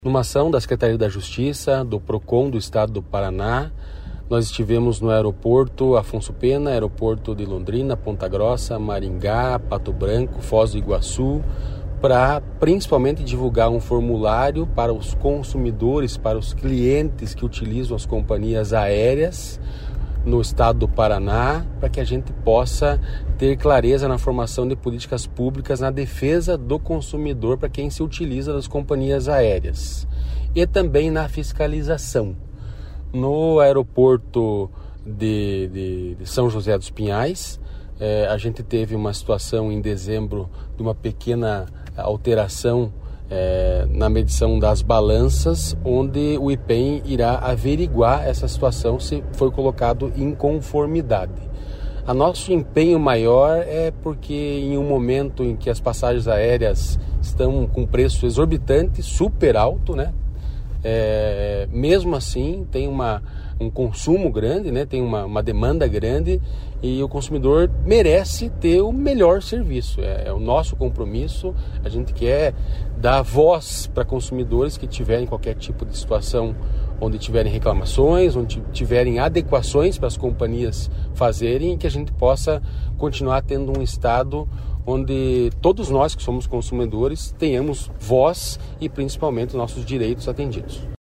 Sonora do secretário Estadual da Justiça e Cidadania, Santin Roveda, sobre a fiscalização do Procon em aeroportos